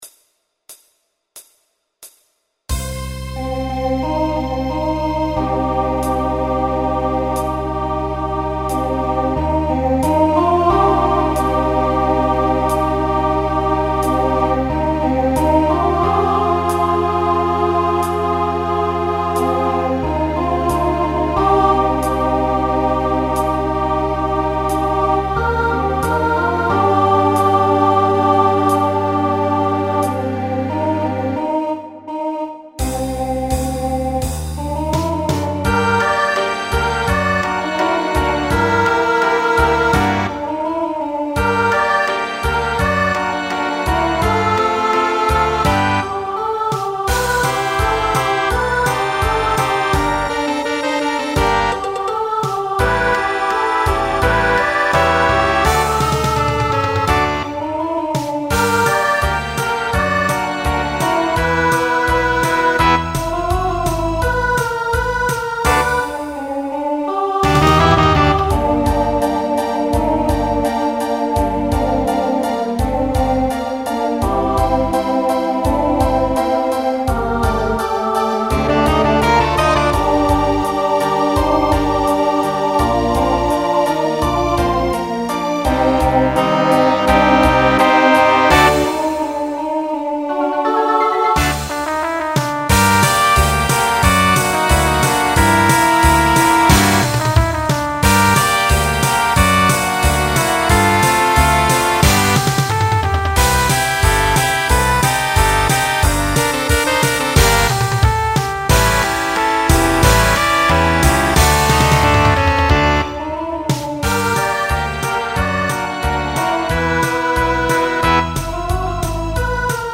Rock
Voicing SSA